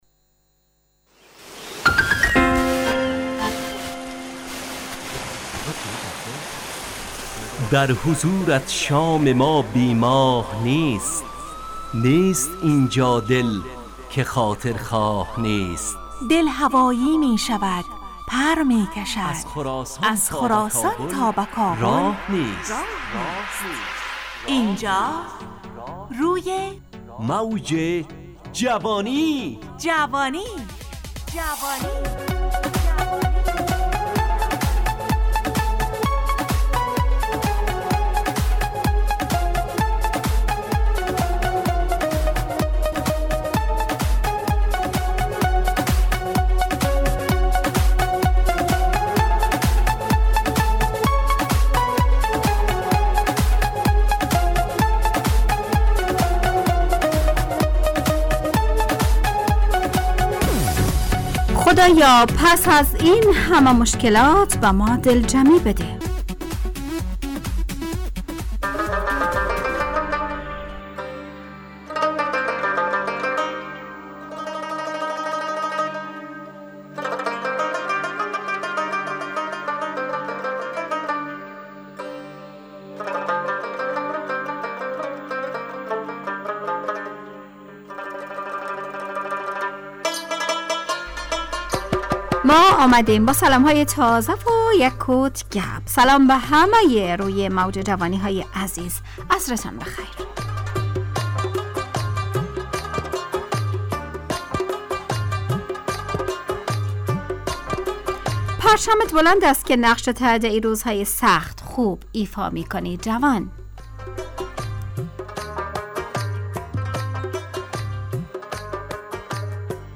همراه با ترانه و موسیقی مدت برنامه 70 دقیقه .